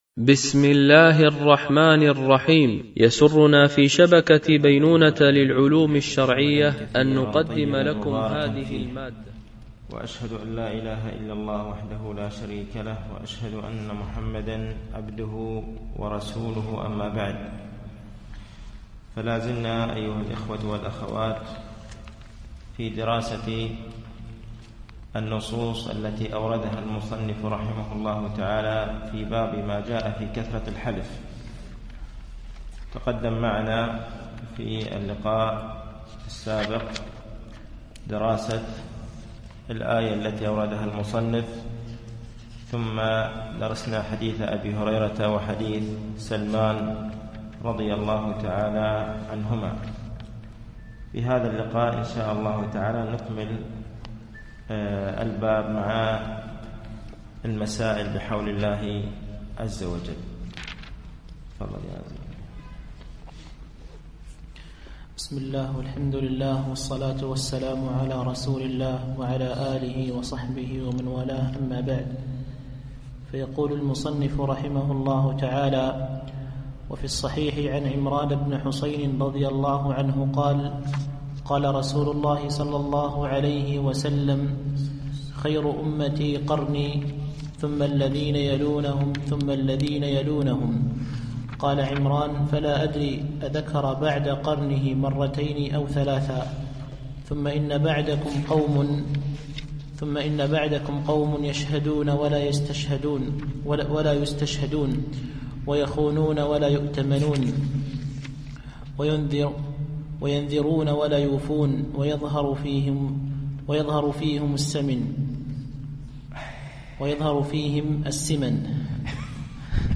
التعليق على القول المفيد على كتاب التوحيد ـ الدرس السادس و الستون بعد المئة